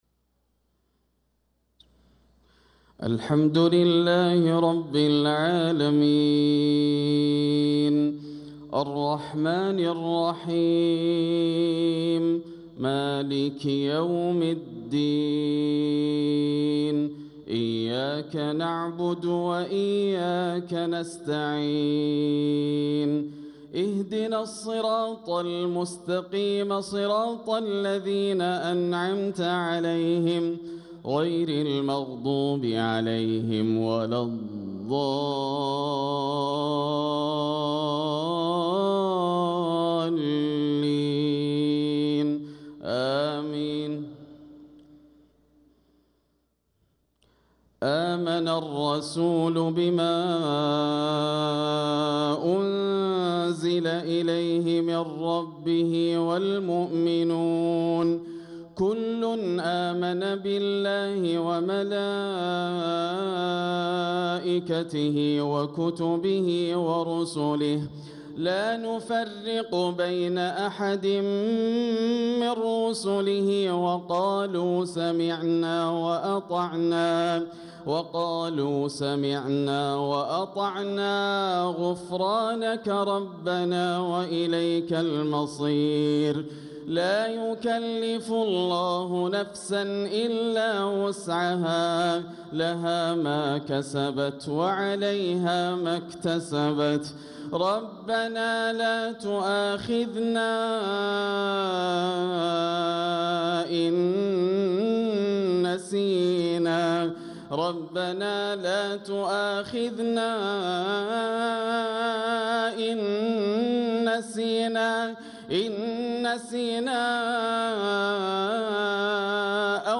صلاة المغرب للقارئ ياسر الدوسري 17 رجب 1446 هـ